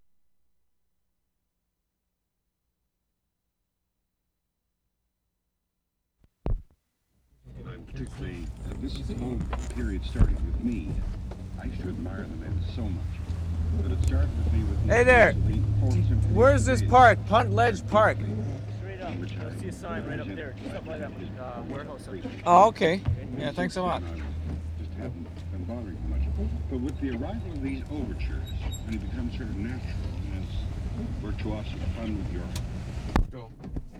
DIRECTIONS TO PUNTLEDGE PARK 0'25"
5. Radio voice in background.